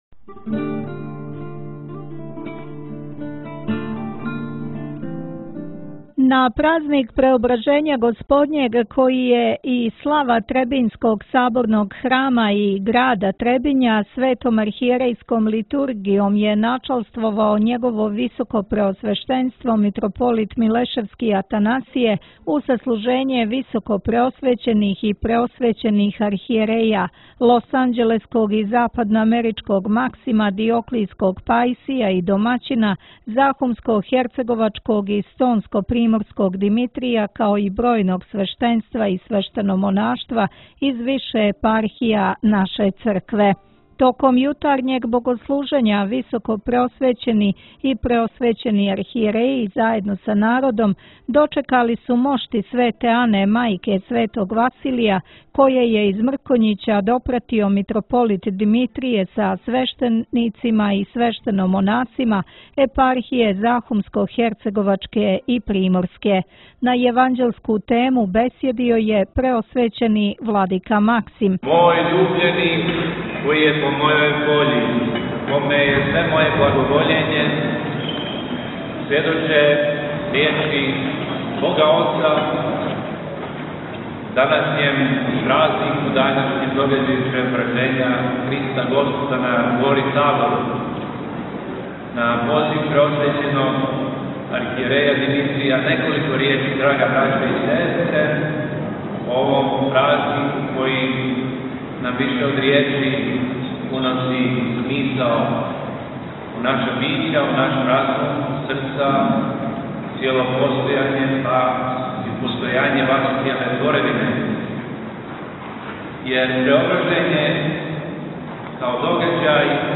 На јеванђељску тему бесједио је Владика Максим.